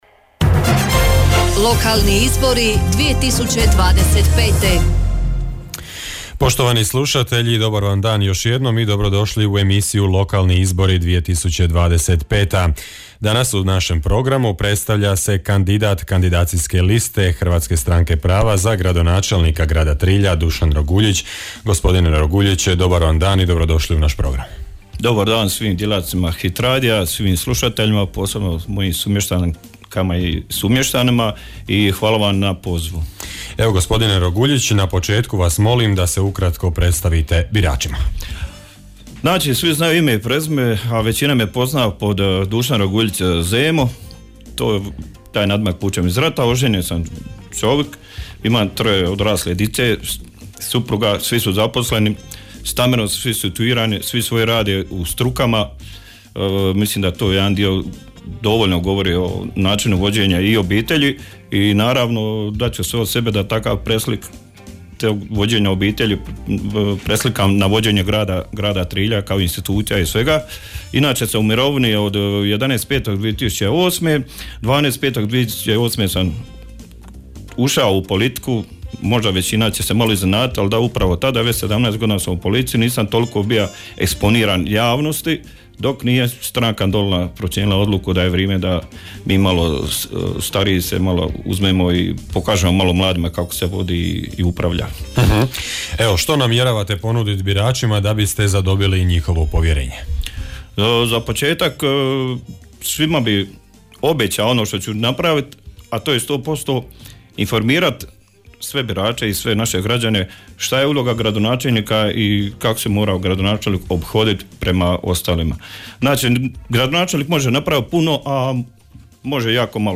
Hit radio prati izbore u 7 jedinica lokalne samouprave (Grad Vrlika, Općina Dicmo, Općina Hrvace, Općina Dugopolje, Općina Otok, Grad Trilj, Grad Sinj). Sve kandidacijske liste i svi kandidati za načelnike odnosno gradonačelnike tijekom službene izborne kampanje imaju pravo na besplatnu emisiju u trajanju do 10 minuta u studiju Hit radija.